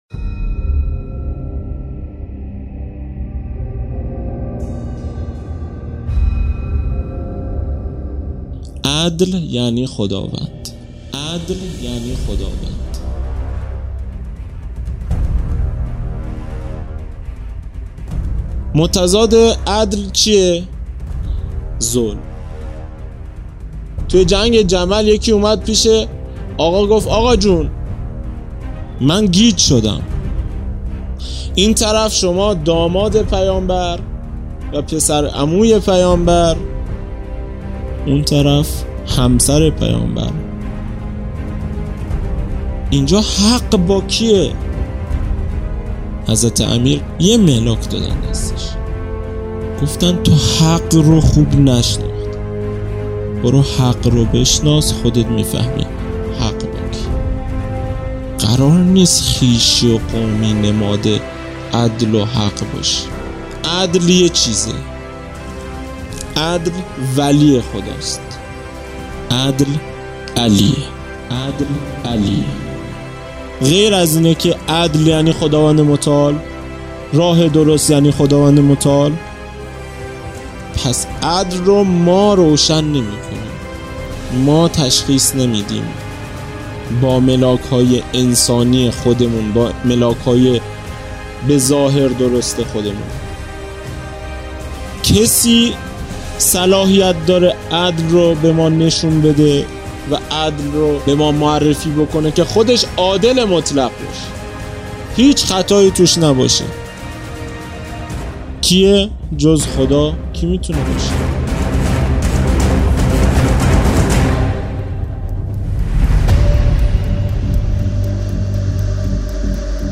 جلسۀ هفتگی